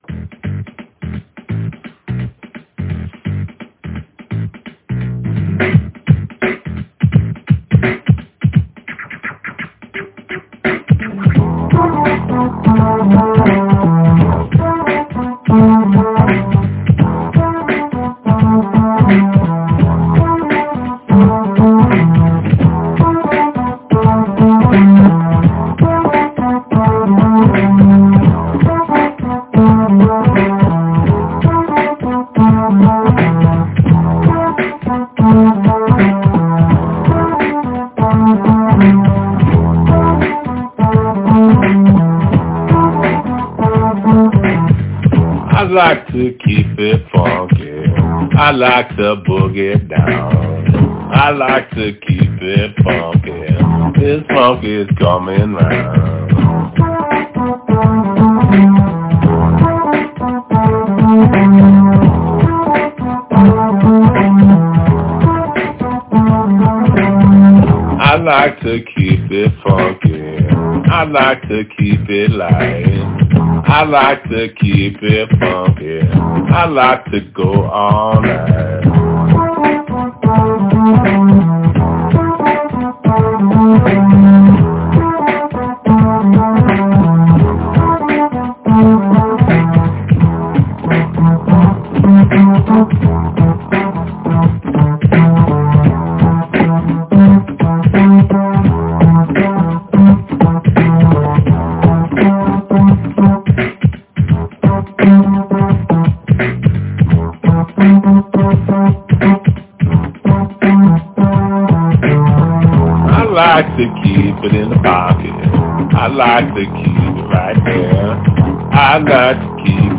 115bpm
keep-it-funky.mp3